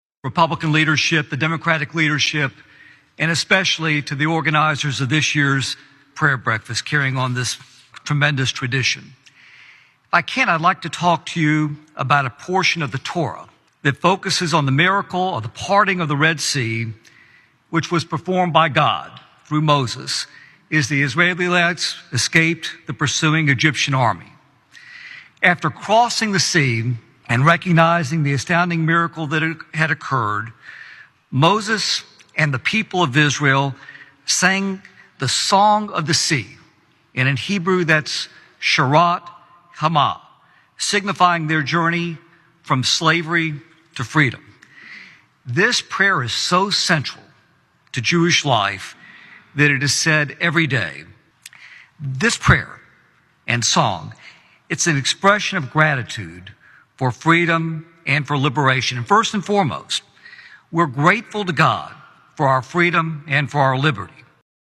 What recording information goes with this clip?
A large crowd attended the 73rd annual National Prayer Breakfast in Washington D.C. last week.